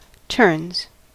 Ääntäminen
Ääntäminen US Haettu sana löytyi näillä lähdekielillä: englanti Turns on sanan turn monikko.